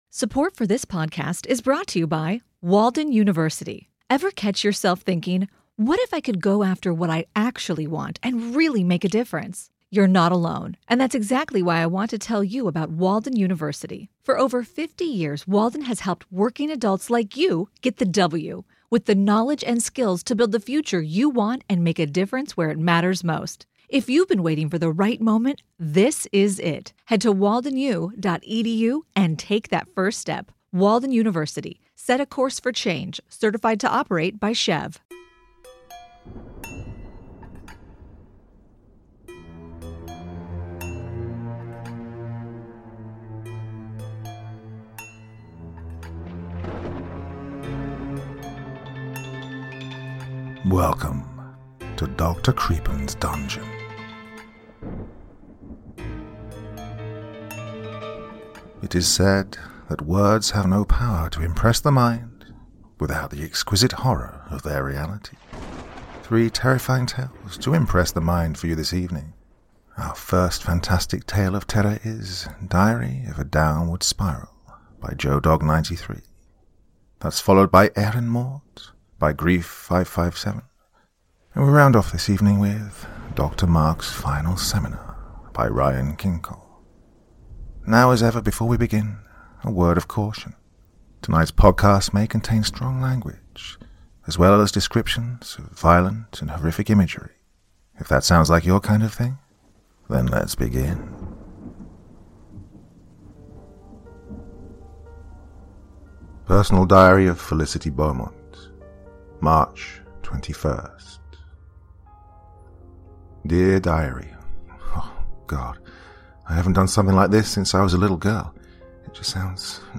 Today’s first fantastic tale of terror is ‘Diary of a Downward Spiral’, an original work by Joe dog 93, kindly shared directly with me for the express purpose of having me exclusively narrate it here for you all.